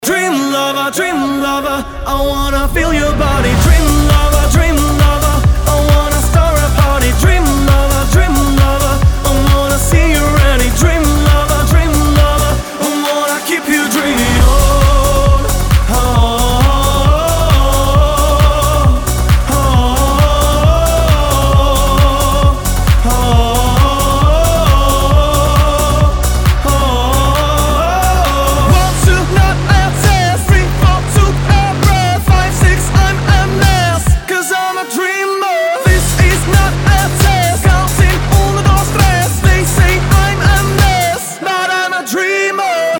Dance Pop